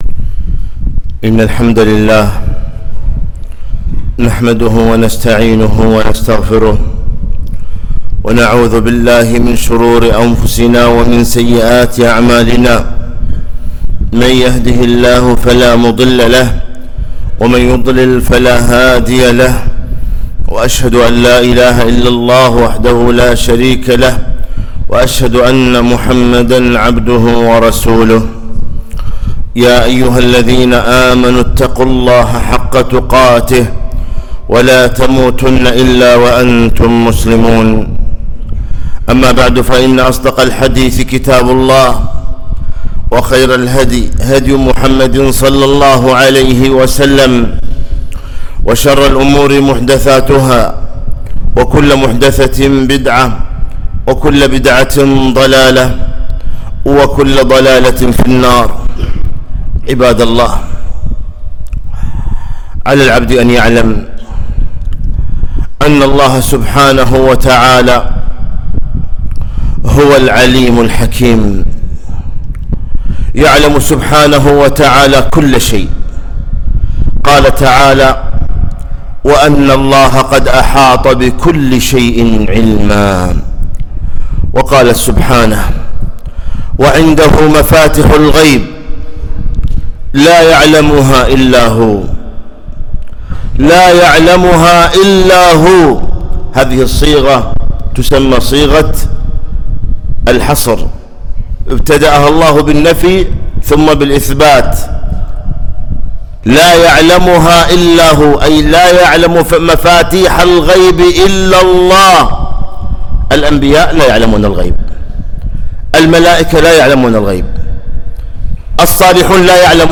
خطبة - احذروا الكهنة والعارافين